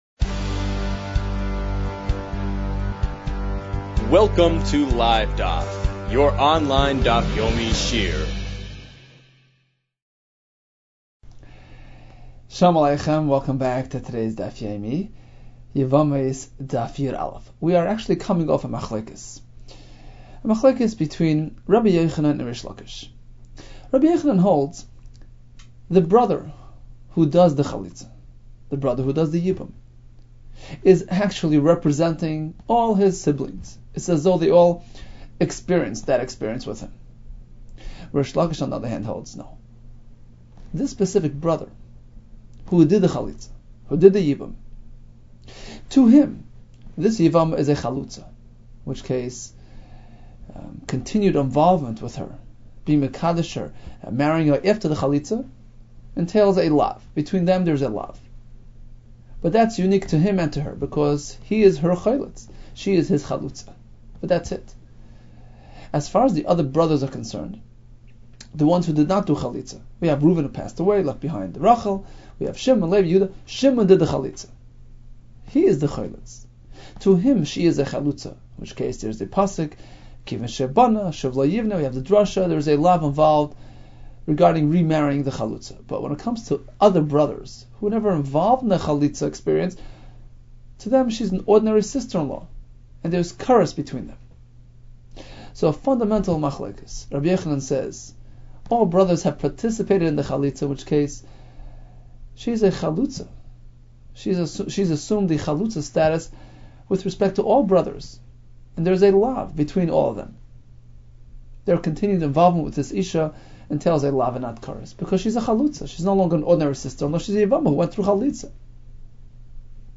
Yevamos 11 - יבמות יא | Daf Yomi Online Shiur | Livedaf